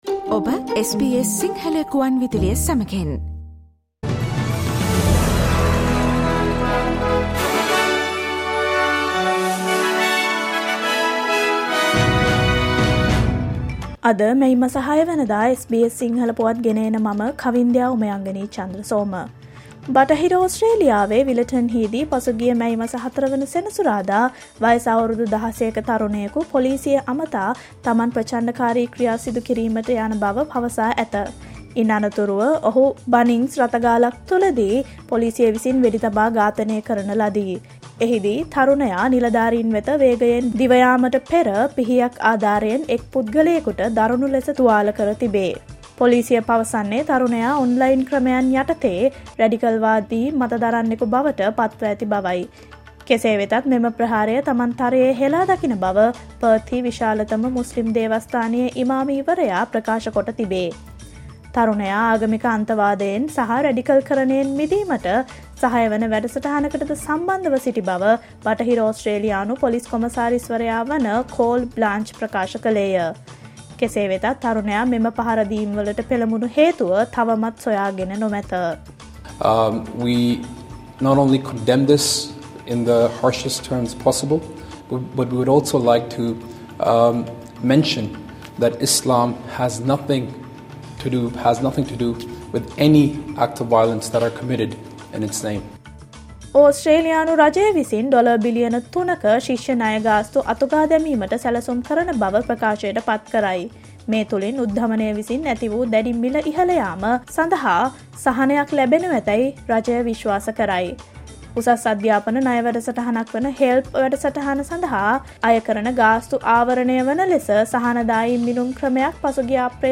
Australia's news in Sinhala, foreign and sports news in brief.